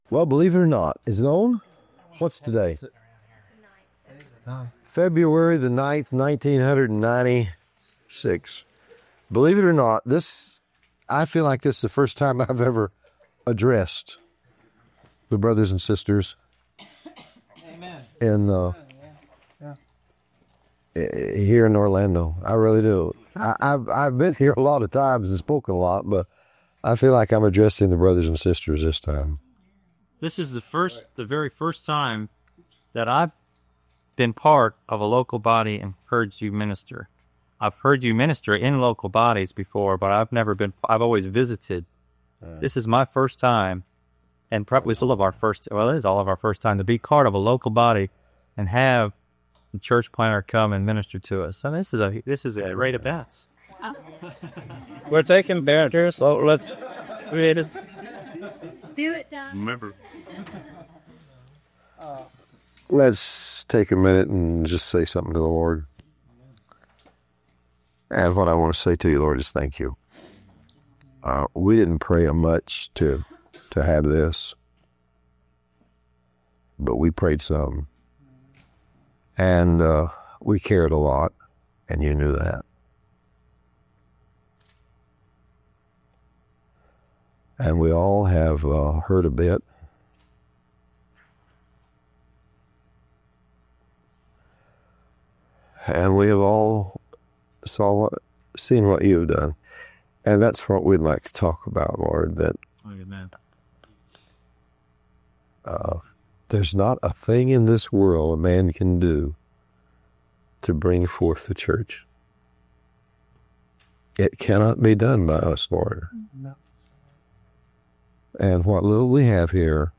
A powerful teaching on seeing the invisible realities of God.